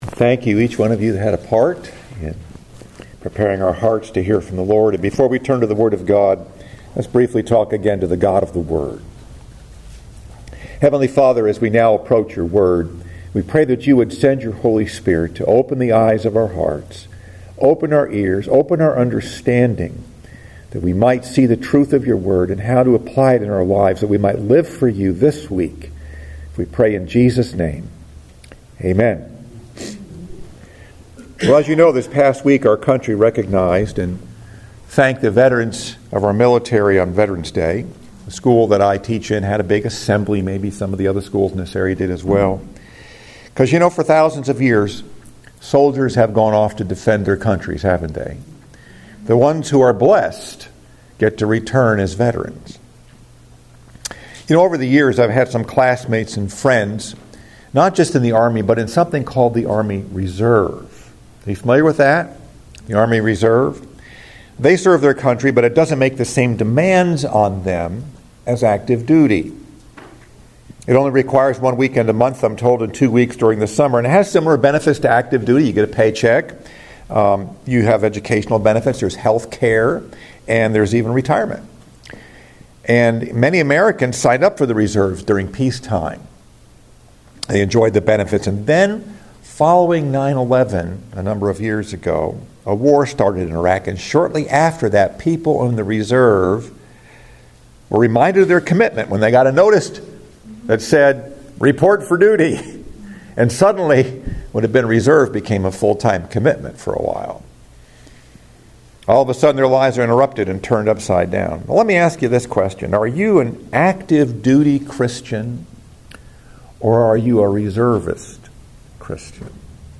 Message: “Report for Duty” – Part 2 Scripture: 2 Corinthians 4:1-6